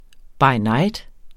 Udtale [ bɑj ˈnɑjd ]